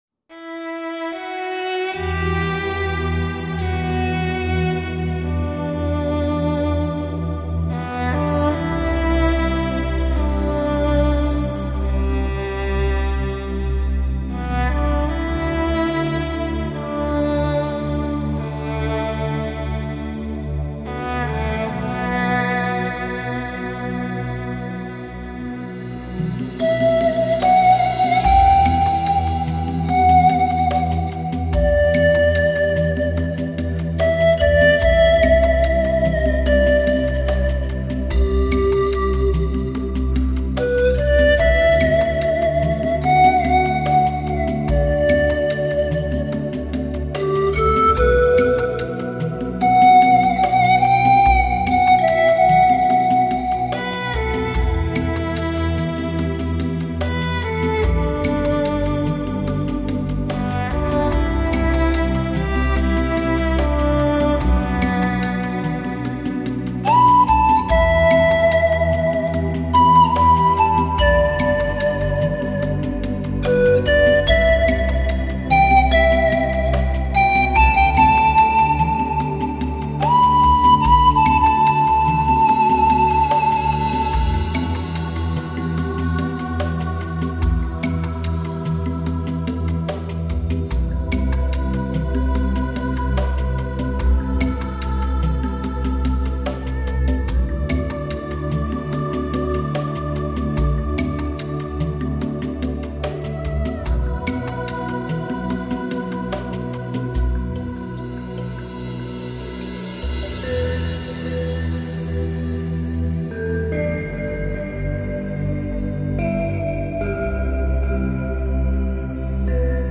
舒缓神经紧张 健康&脑波，自愈功能，镇静减压